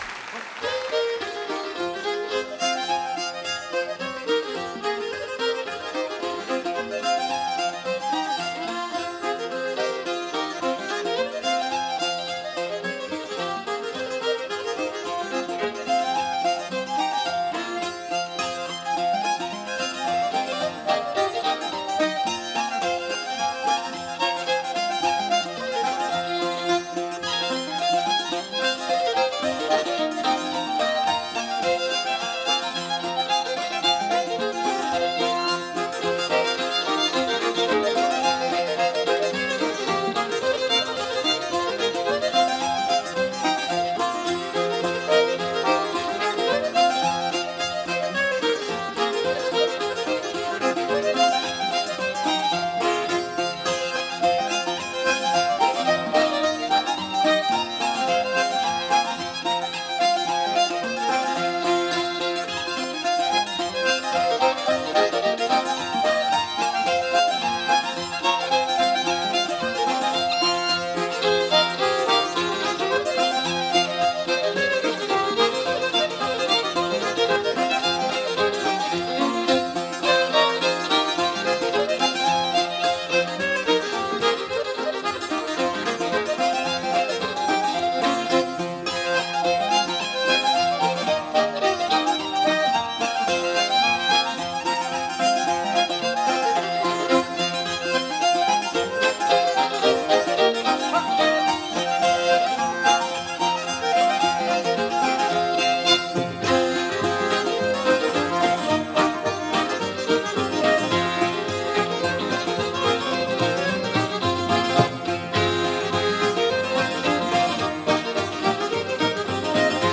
风笛、小提琴等乐器逐渐发展出爱尔兰音乐的固有特色。
幸好，这是一张现场录音的演奏会唱片。